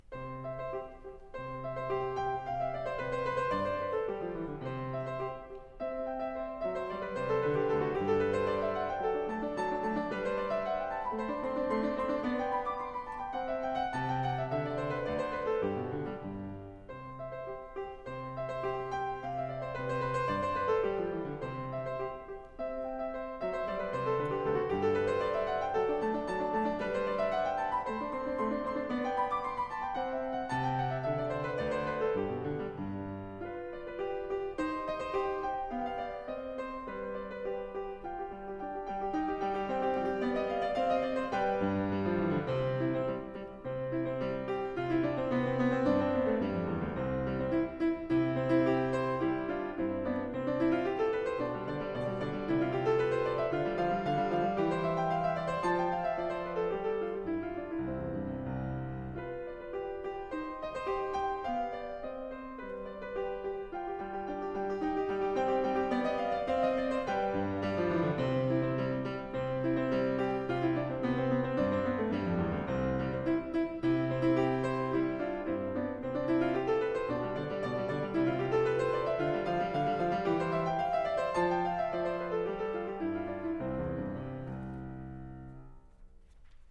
The selections below are from a concert I played called "The Essential Pianist".